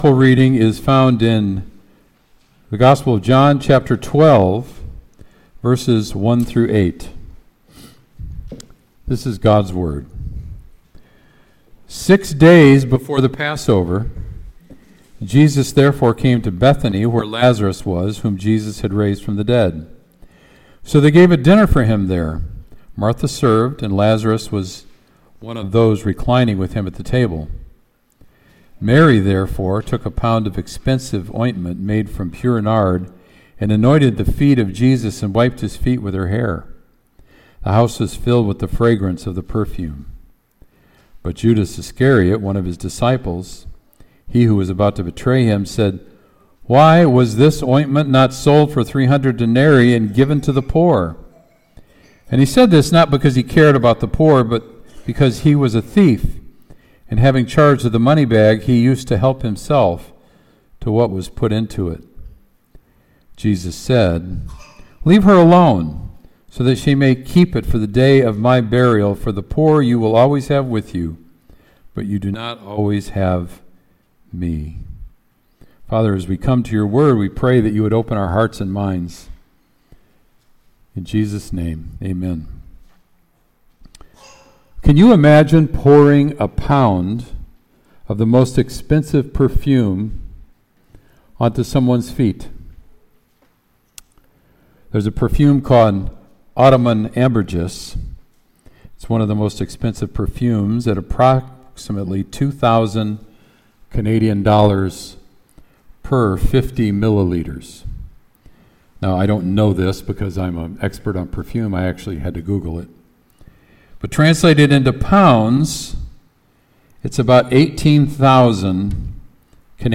Sermon “Mary Anoints Jesus”